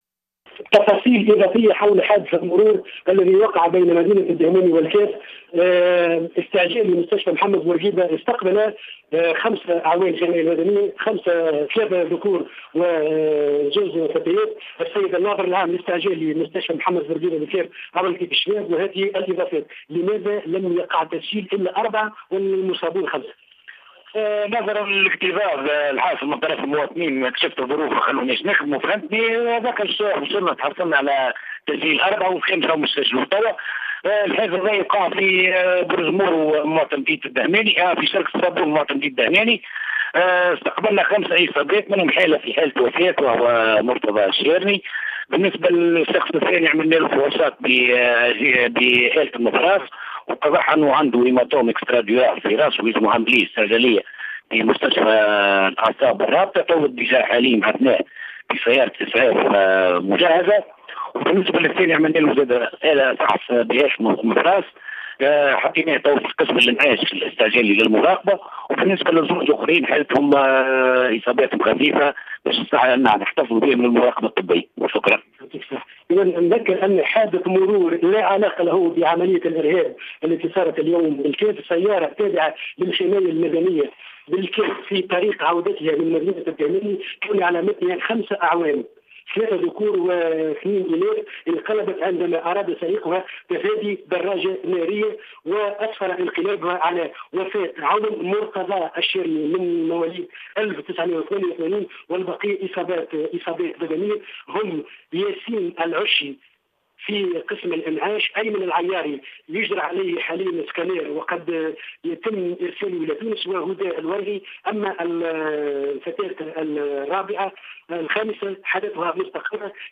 أكثر تفاصيل مع مراسلنا